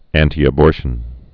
(ăntē-ə-bôrshən, ăntī-)